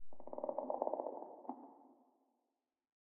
Minecraft Version Minecraft Version snapshot Latest Release | Latest Snapshot snapshot / assets / minecraft / sounds / block / creaking_heart / hurt / trail4.ogg Compare With Compare With Latest Release | Latest Snapshot